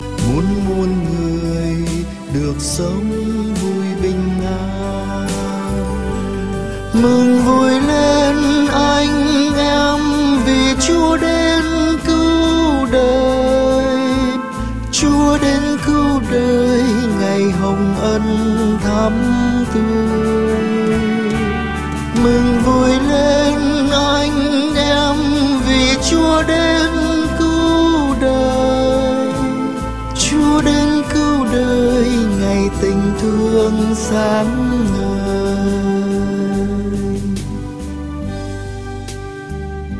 MungVuiLen_Sop.mp3